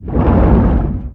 Sfx_creature_chelicerate_exoattack_loop_water_os_03.ogg